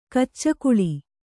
♪ kaccakuḷi